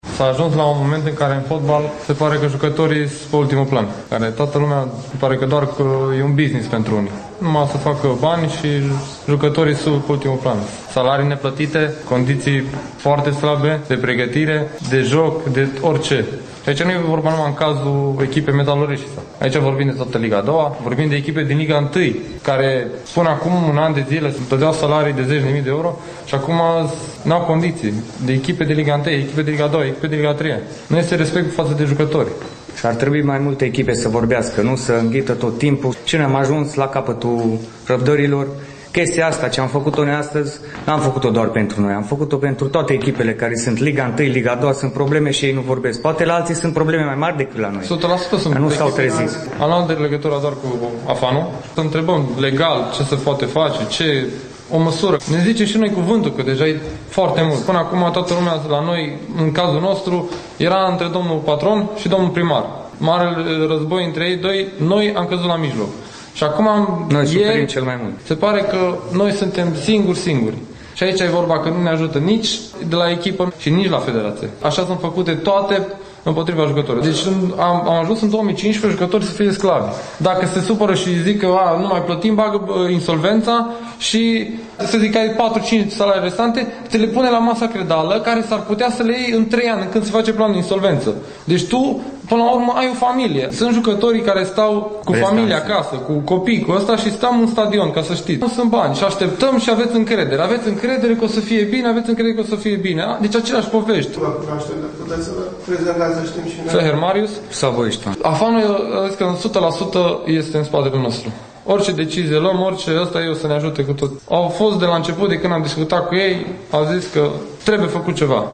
Metalul Reşiţa a atras atenţia presei sportive din România la finalul săptămânii trecute, mai precis sâmbătă, 14 noiembrie, prin refuzul fotbaliştilor de a juca partida de la Satu Mare, împotriva Olimpiei. La conferinţa de presă care a urmat